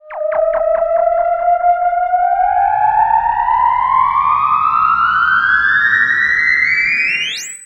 Theremin_Swoop_10.wav